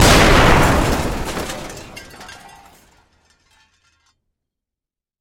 Звук взрыва танка